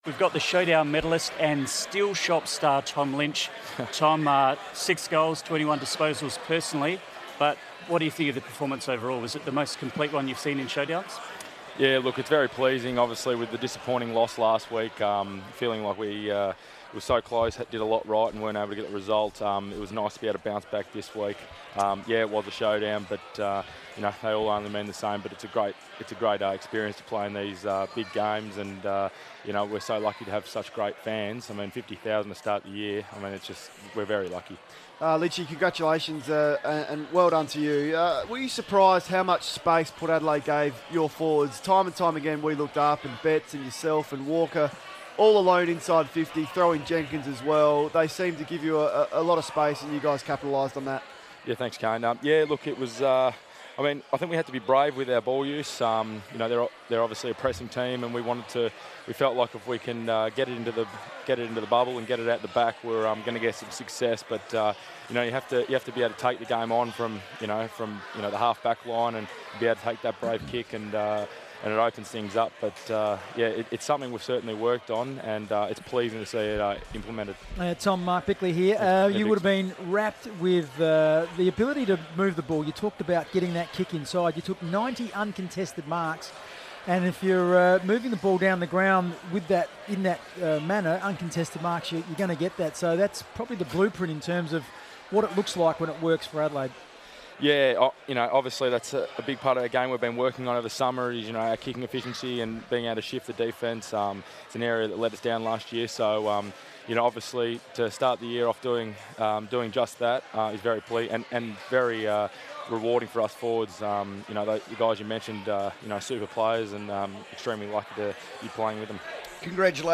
Tom Lynch spoke on FIVEaa radio after winning the Showdown Medal in Sunday's win over Port Adelaide